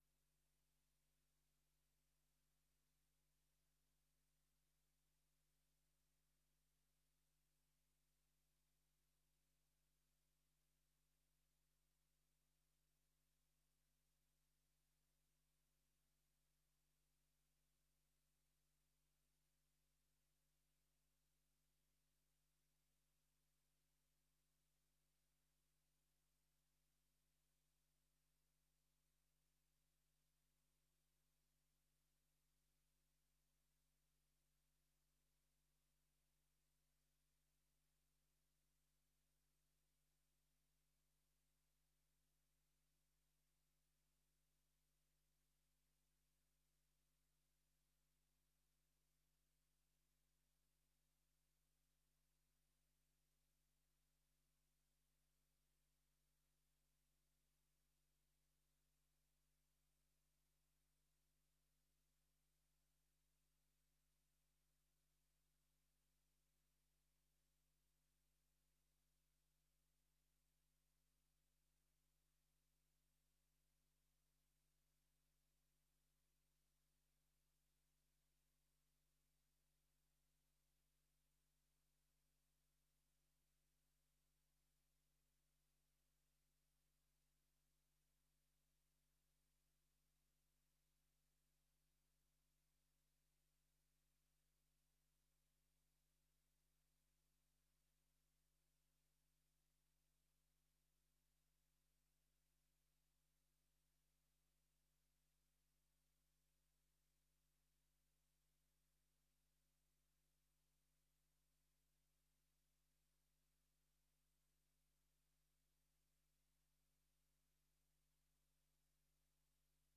Extra raadsvergadering 23 augustus 2024 13:15:00, Gemeente Goirle
Download de volledige audio van deze vergadering
Locatie: Raadzaal